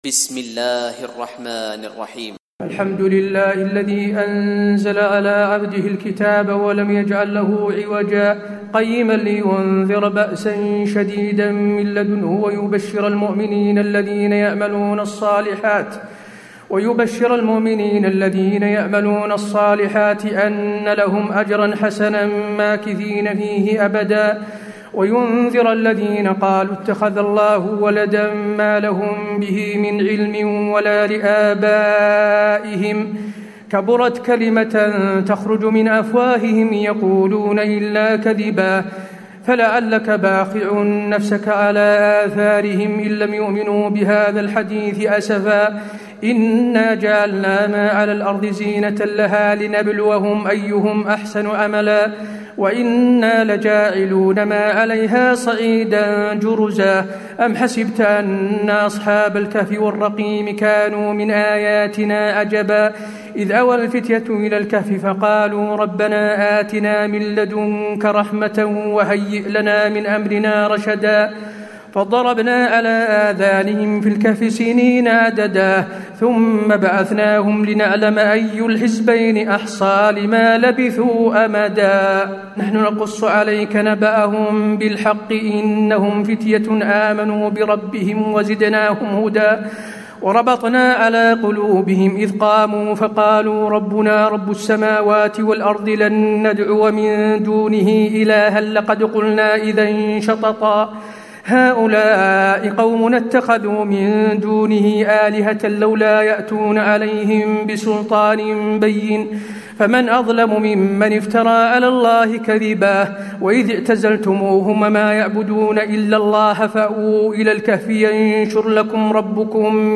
تراويح الليلة الرابعة عشر رمضان 1436هـ من سورة الكهف (1-59) Taraweeh 14 st night Ramadan 1436H from Surah Al-Kahf > تراويح الحرم النبوي عام 1436 🕌 > التراويح - تلاوات الحرمين